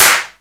Index of /90_sSampleCDs/Club_Techno/Percussion/Clap
Clap_01.wav